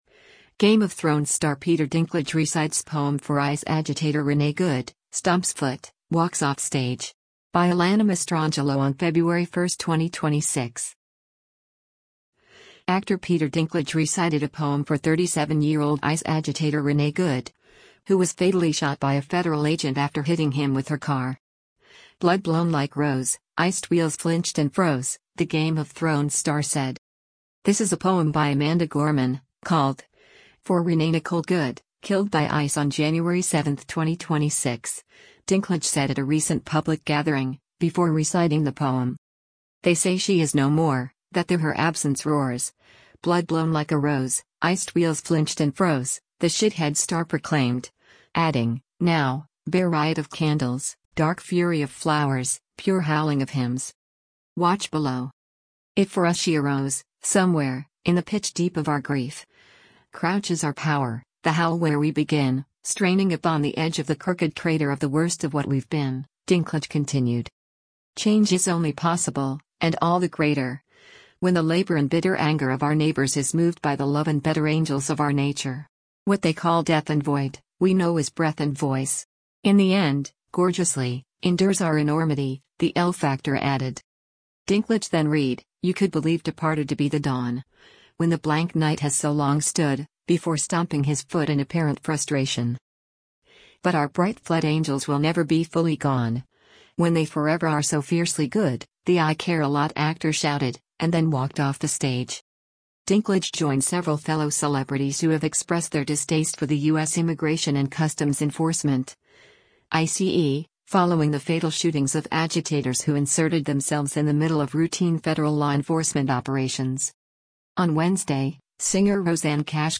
Dinklage then read, “You could believe departed to be the dawn, when the blank night has so long stood,” before stomping his foot in apparent frustration.